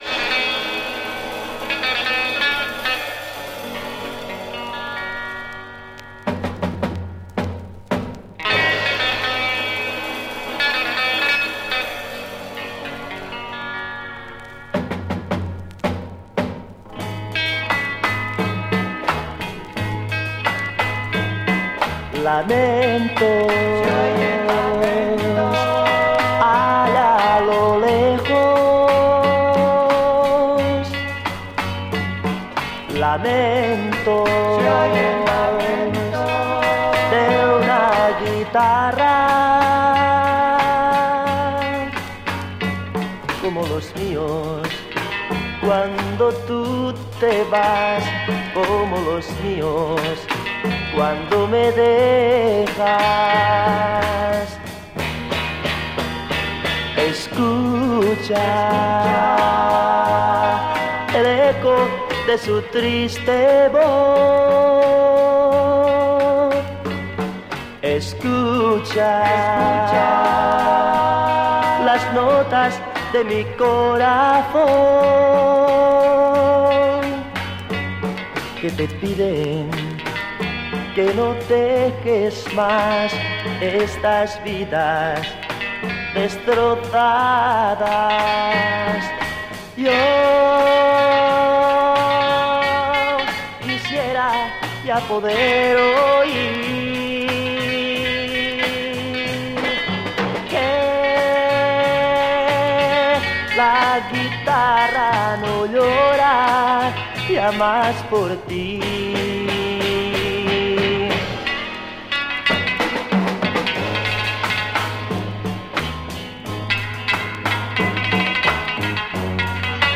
Mega rare Spanish EP Garage Freakbeat